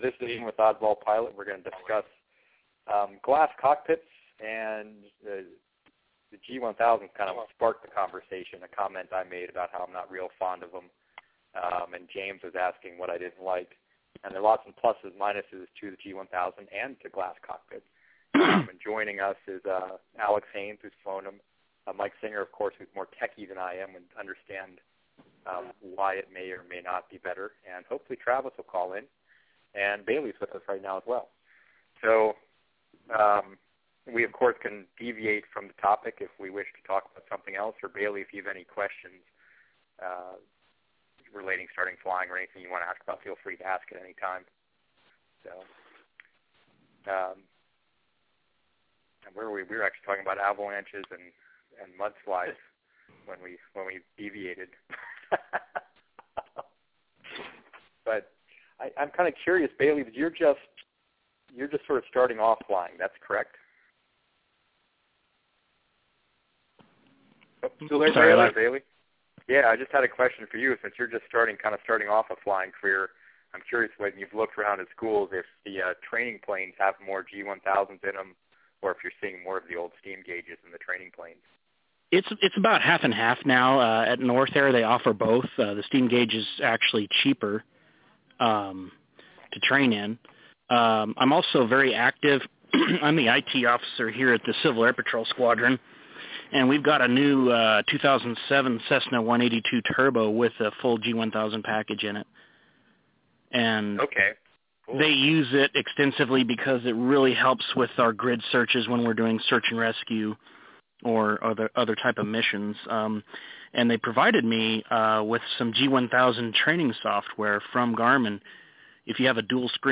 In our fourth Q&A call for Oddball Pilot members we talked about glass cockpits.
It was a group discussion more than a Q&A per se.